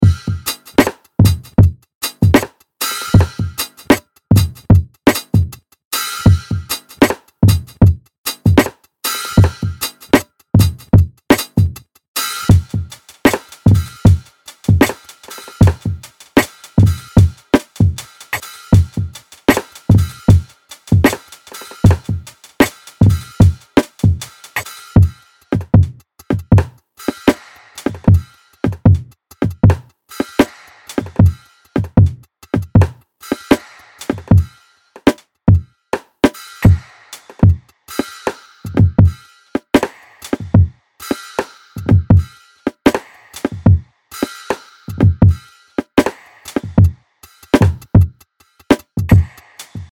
グルーヴィーなローファイ・バウンス、グリッドを超越するファンキー
・にじみ出るローファイ、遊び心、グリッドを超えるグルーブ感あふれるビート
プリセットデモ